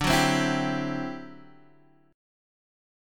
D11 chord {x 5 4 5 5 3} chord